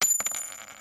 coin_to_coin.wav